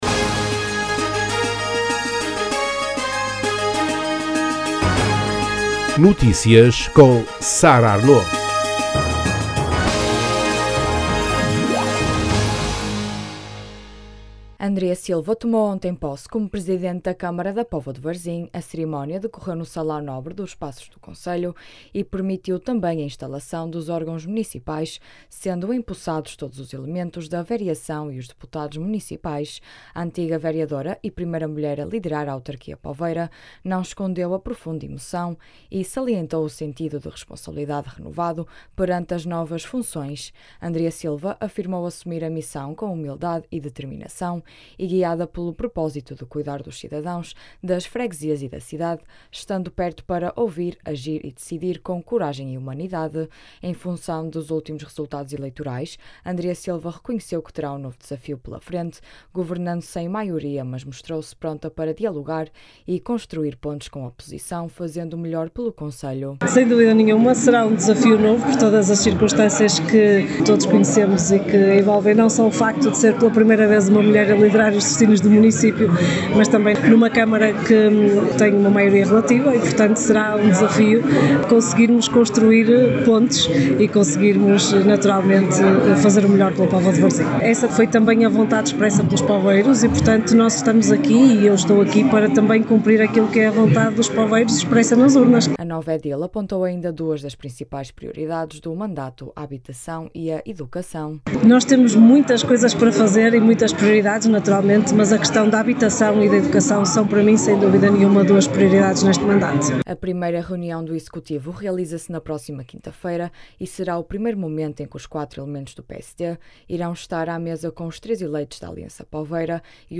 A cerimónia decorreu no salão nobre dos Paços do Concelho e permitiu também a instalação dos órgãos municipais, sendo empossados todos os elementos da vereação e os deputados municipais.
As declarações podem ser ouvidas na edição local.